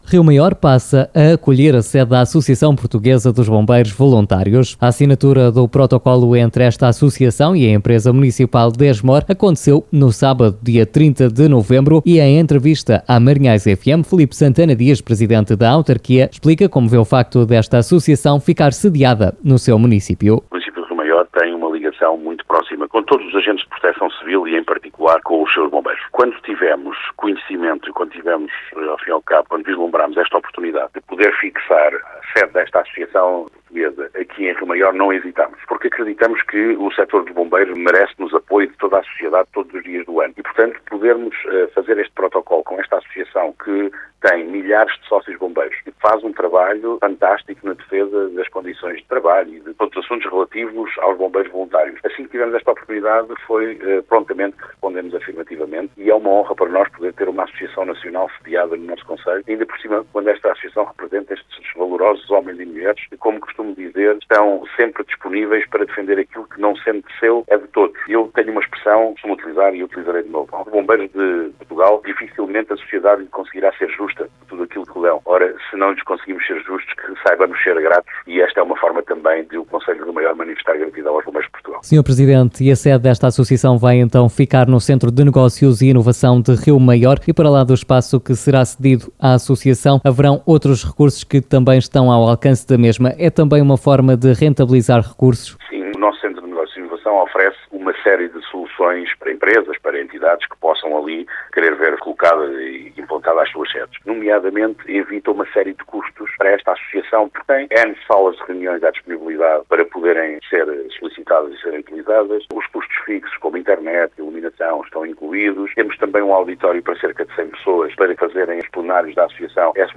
Escute, aqui, as declarações do Presidente da Câmara Municipal de Rio Maior, Filipe Santana Dias, à Rádio Marinhais: